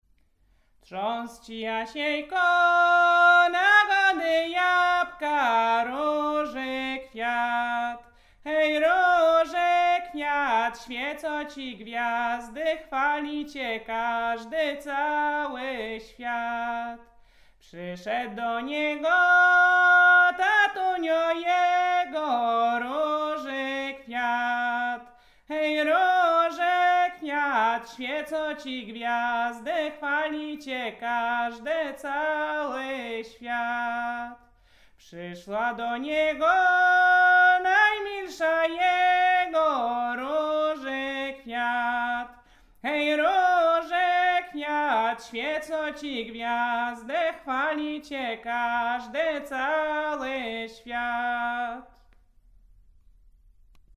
performer
Lubelszczyzna
Kolęda